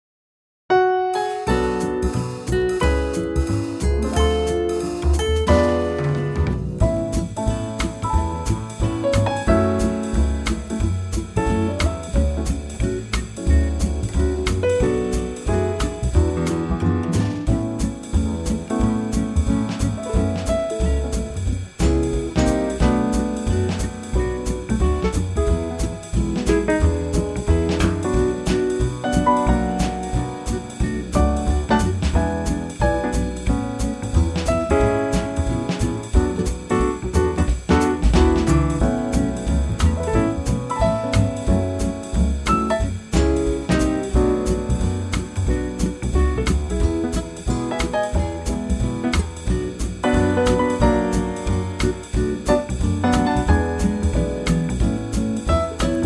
Unique Backing Tracks
key - A - vocal range - B to C
Great swinging Quartet arrangement